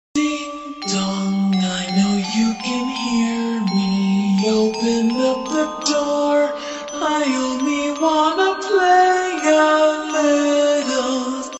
Horror version of Gumball characters